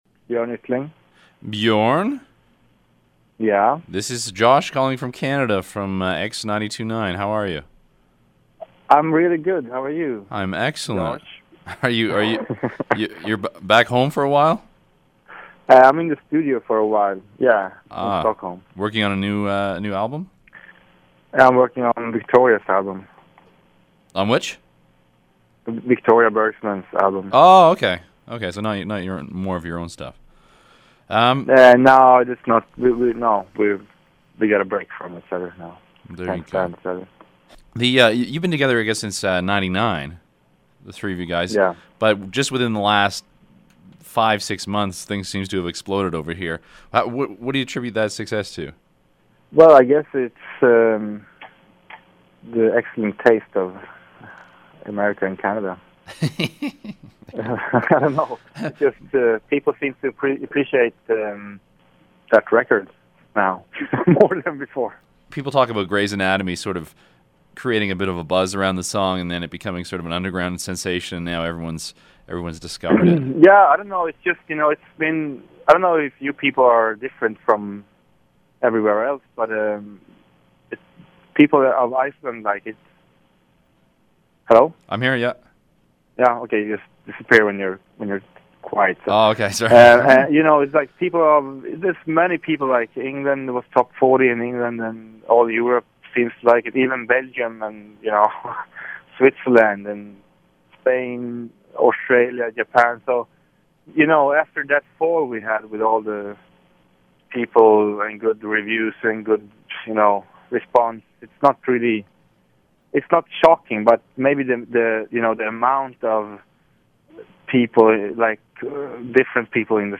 He was in a studio in Stockholm Sweden.